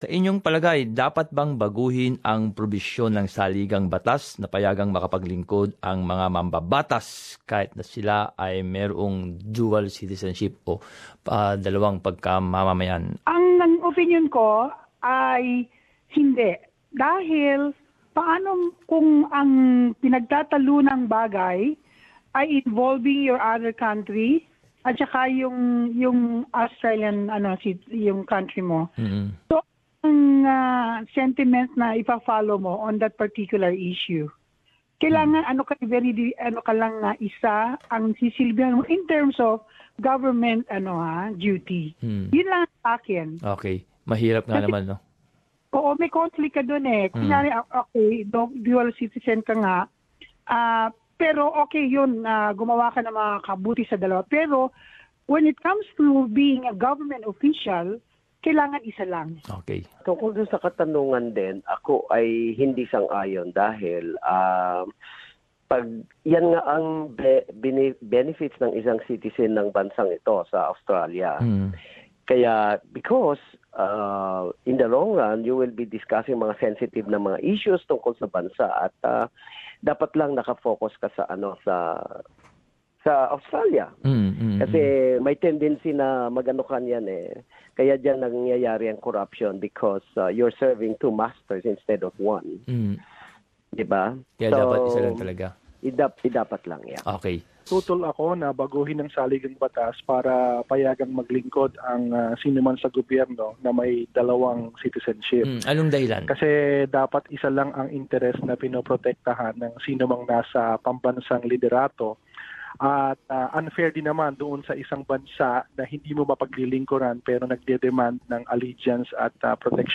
Talkback: Should the constitution be changed to allow dual citizenships for senators, MPs